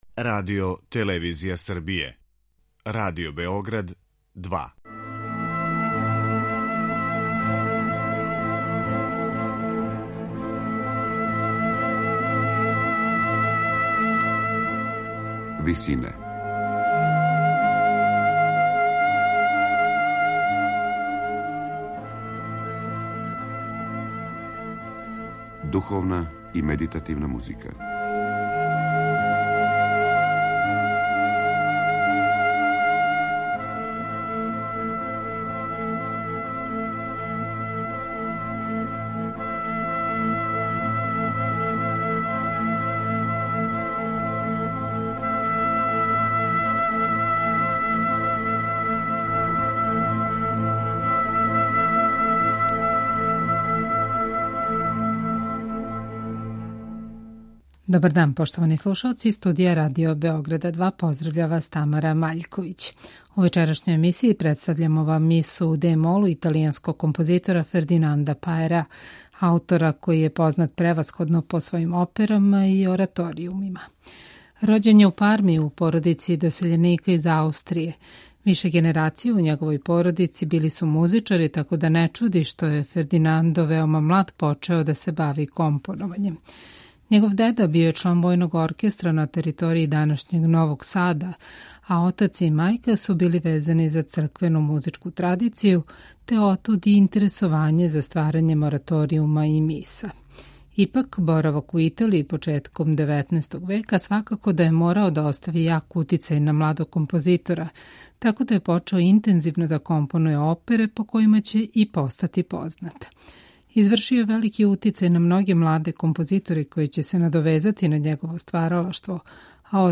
медитативне и духовне композиције
Утицај оперског стила може се уочити и у његовим духовним остварењима, пре свега у опсежној Миси у де-молу из 1805. године.